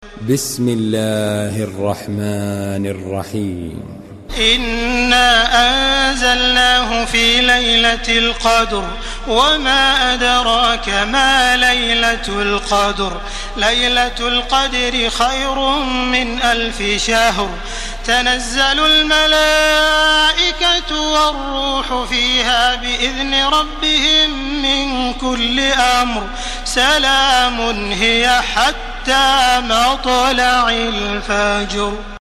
تراويح الحرم المكي 1431
مرتل